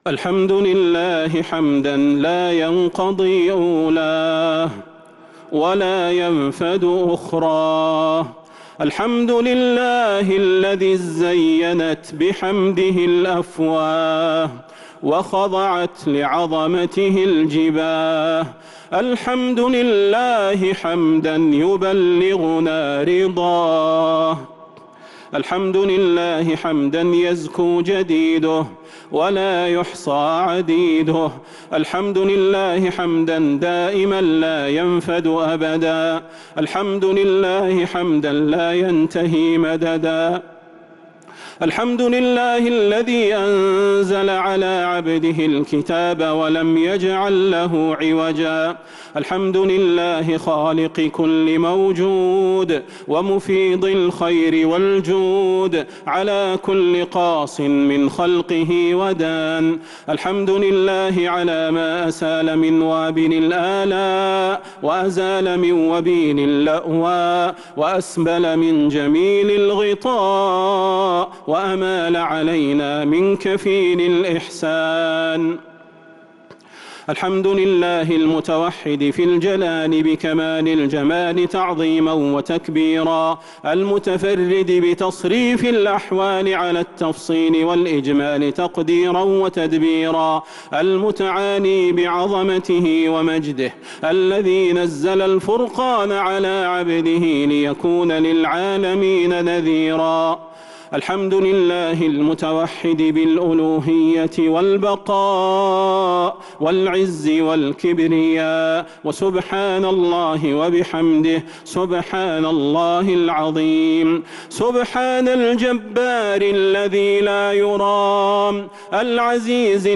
دعاء ختم القرآن ليلة 29 رمضان 1443هـ | Dua for the night of 29 Ramadan 1443H > تراويح الحرم النبوي عام 1443 🕌 > التراويح - تلاوات الحرمين